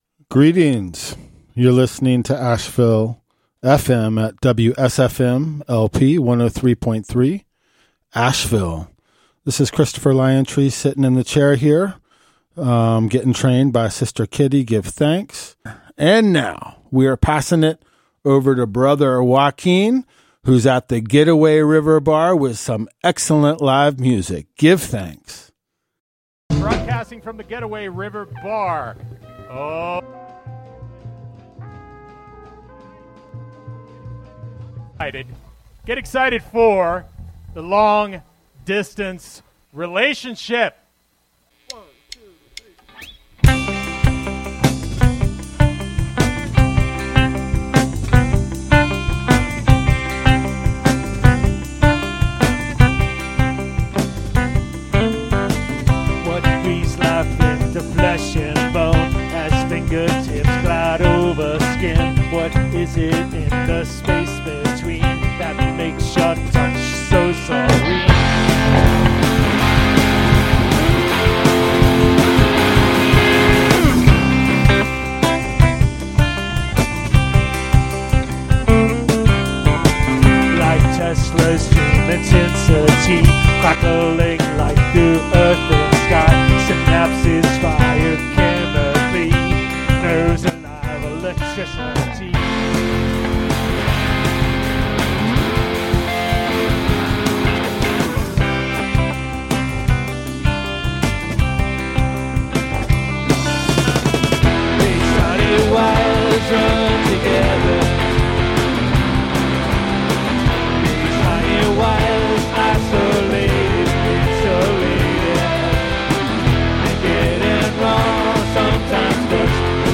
Explicit language warning https